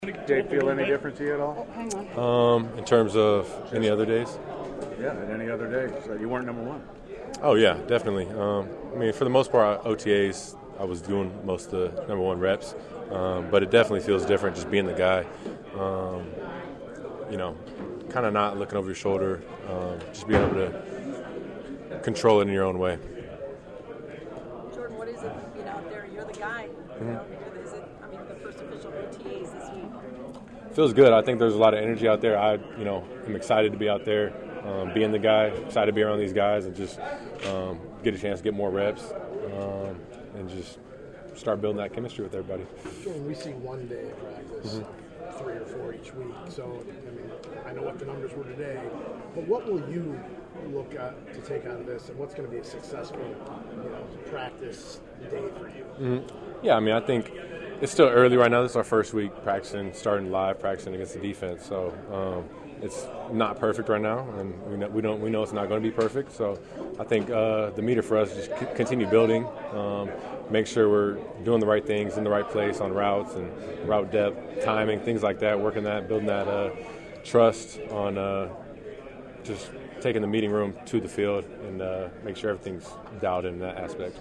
Love held court in the locker room post-practice and I fired off the first question on the dawn of his career as a starter: